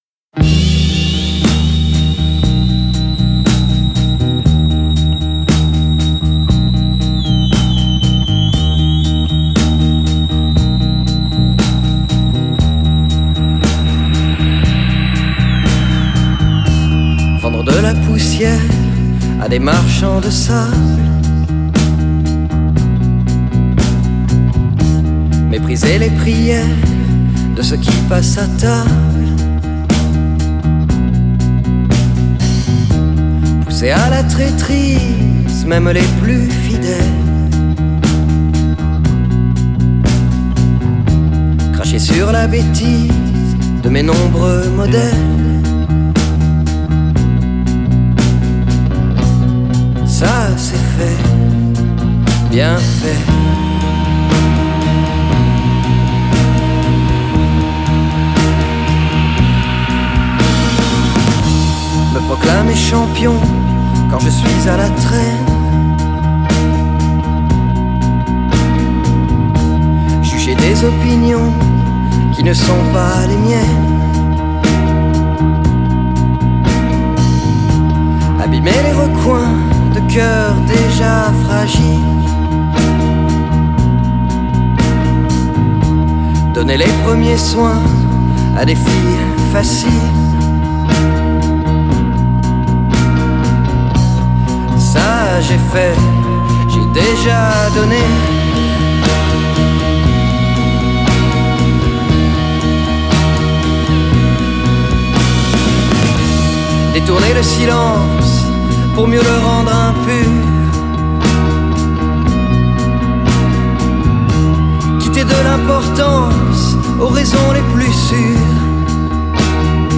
chanson française
un son un peu plus rock et incisif